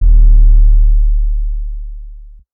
D (Antidote 808).wav